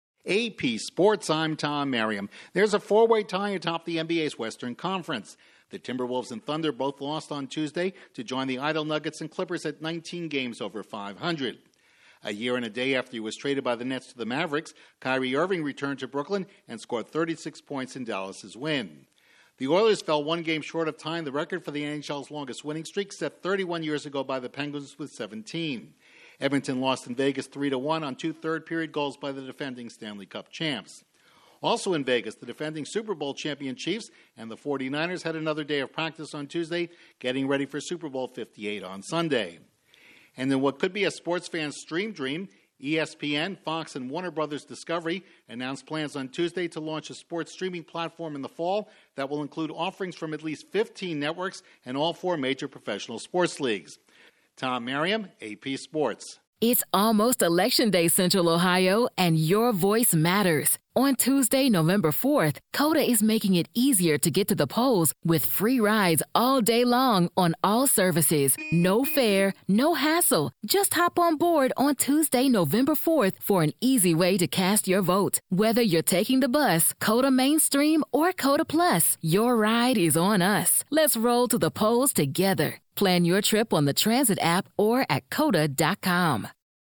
Correspondent